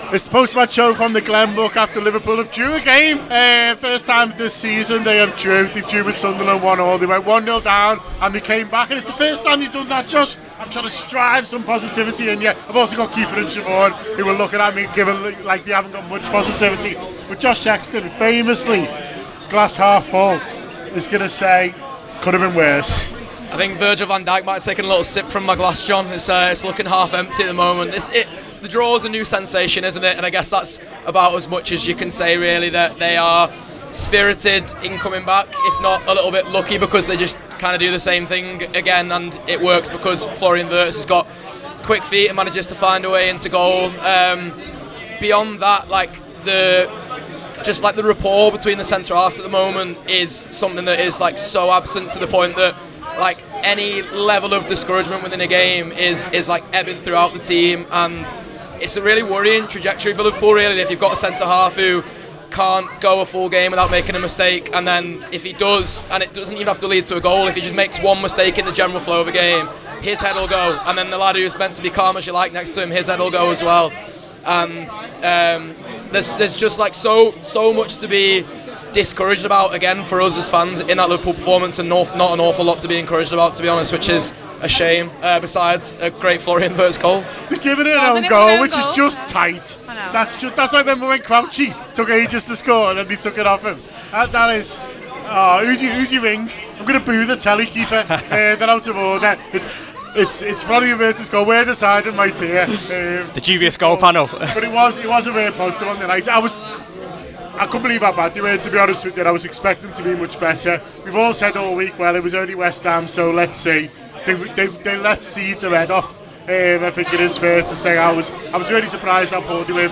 Below is a clip from the show – subscribe to The Anfield Wrap for more on the 20 x Champions Of England